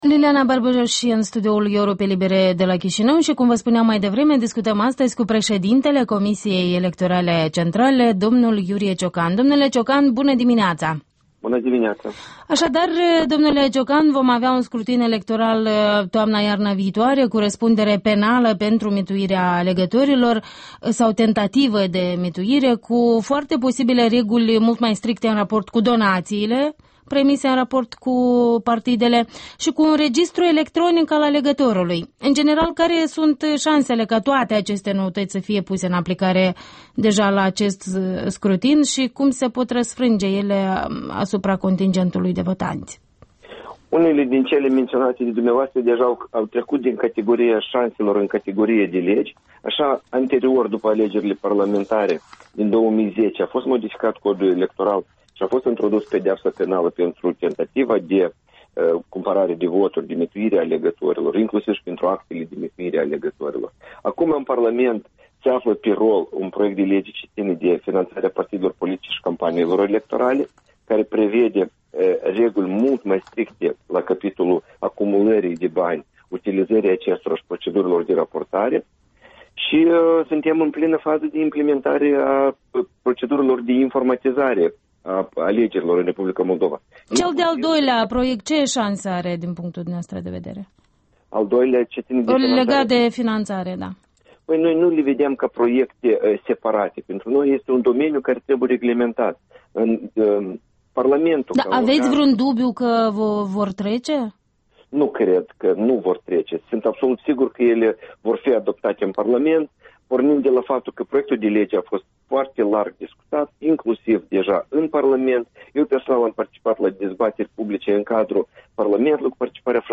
Interviul dimineții: cu Iurie Ciocan despre noua legislație în vederea alegerilor